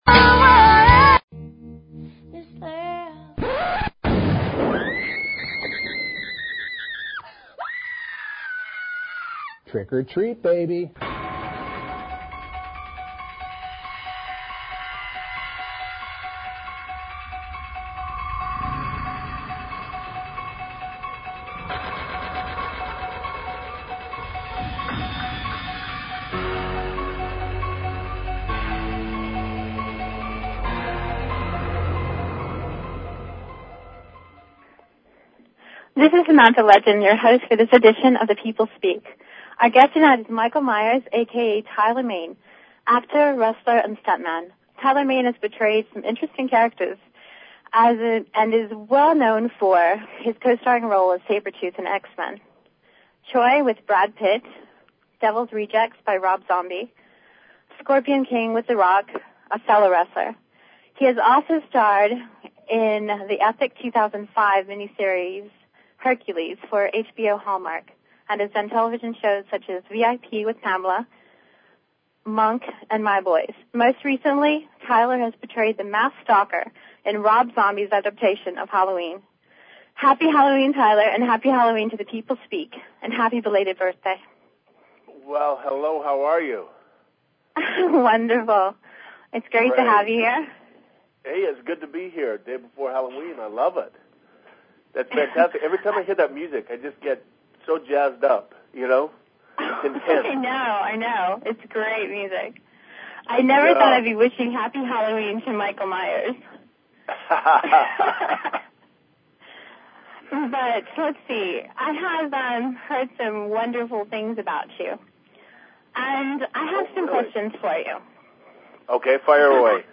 Guest, Tyler Mane